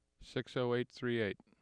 7. Spectrogram and AIF tracks for speech utterance “